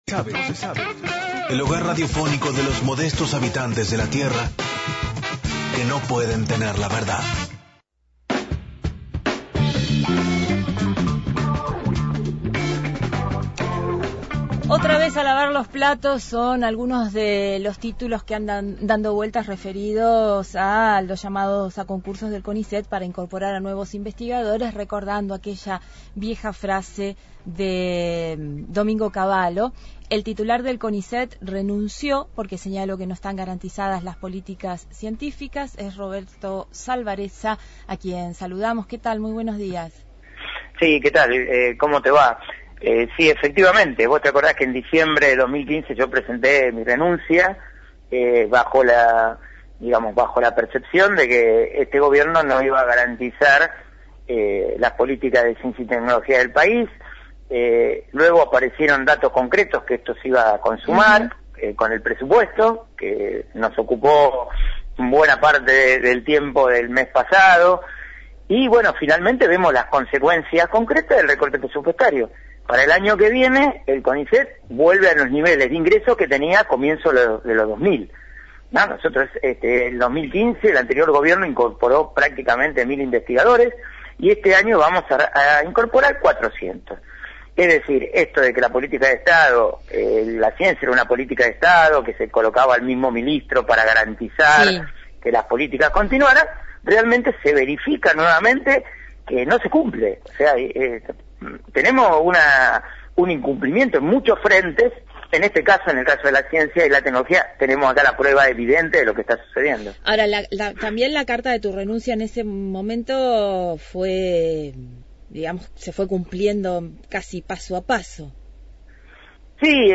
Entrevista al ex titular del Conicet, Roberto Salvareza, sobre la situación en el Conicet y el recorte presupuestario a la ciencia en el país. Programa: NO Se Sabe.